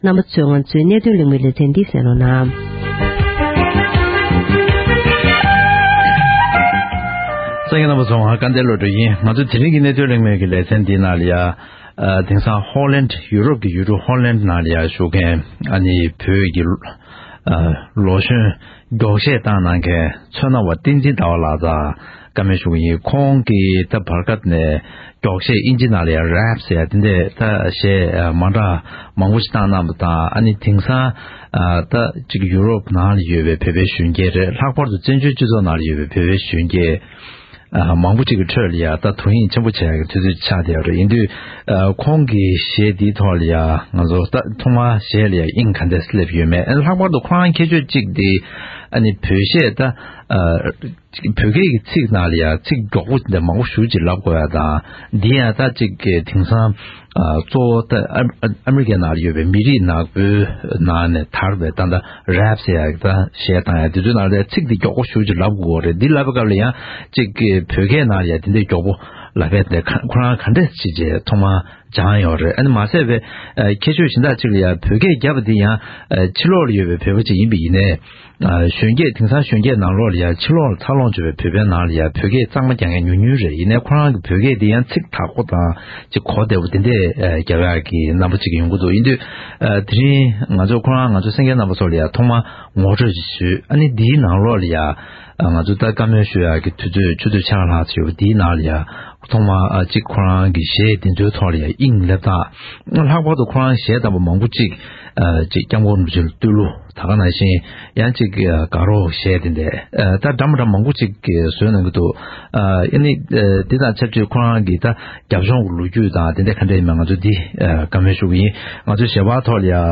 གླེང་མོལ་ཞུས་པར་གསན་རོགས༎